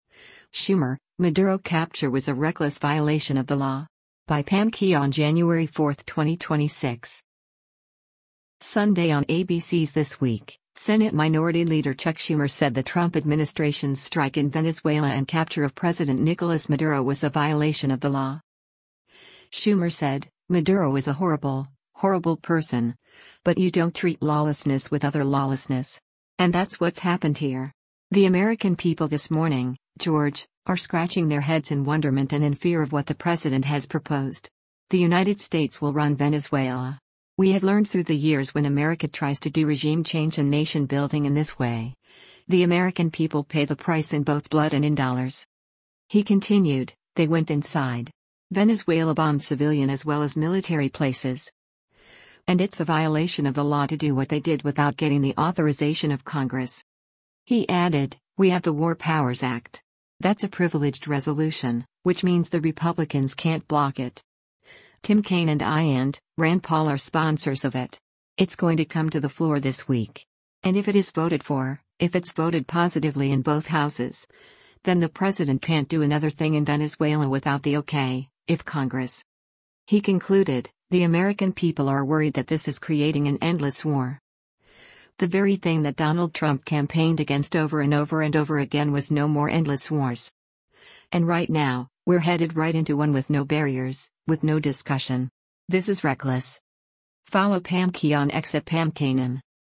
Sunday on ABC’s “This Week,” Senate Minority Leader Chuck Schumer said the Trump administration’s  strike in Venezuela and capture of President Nicolas Maduro was a “violation of the law.”